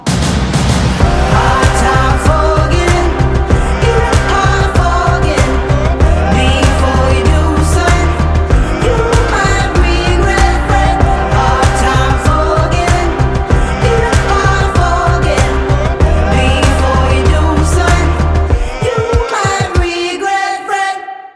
• Качество: 320, Stereo
красивые
soul